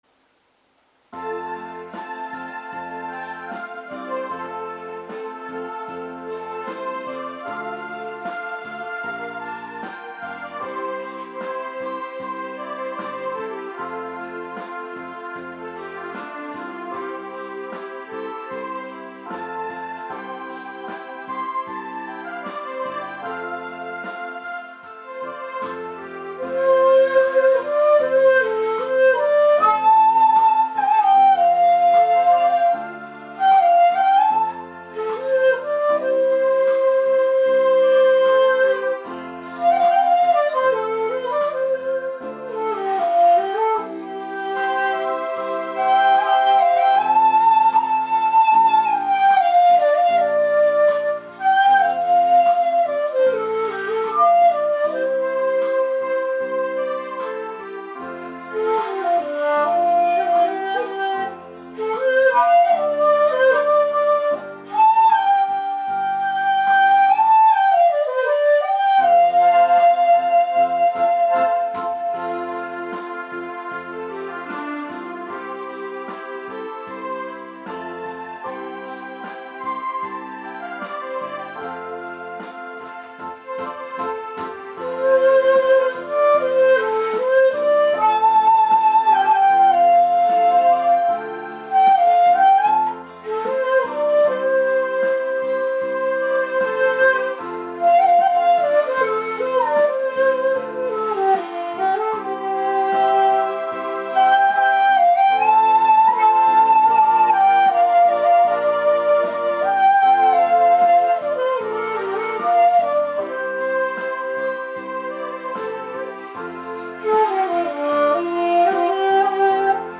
七孔の地塗り管もあるのですが、今回は音色重視ということで敢えて地無し延べ竹を使いました。
ちょっと聴いたところ音程を無視して本人だけ気分良く吹き散らかしているように聞こえますが、ほんとうは厳しい吹奏でした。